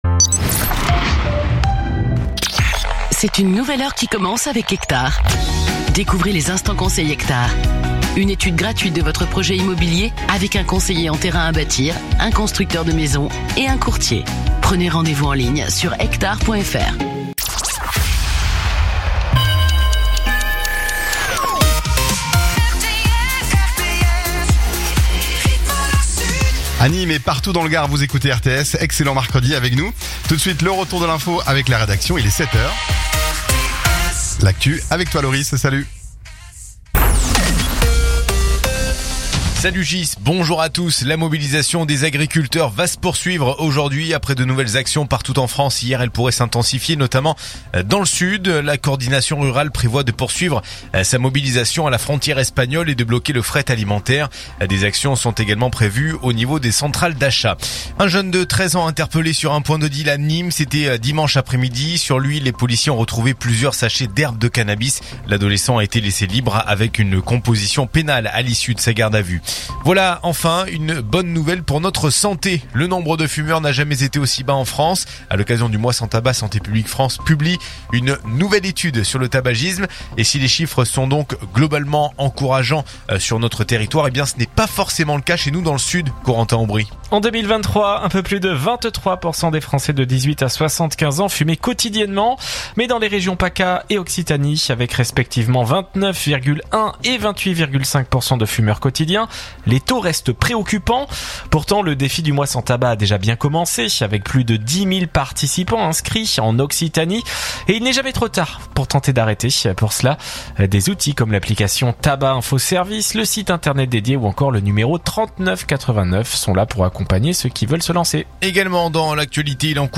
info_nimes_208.mp3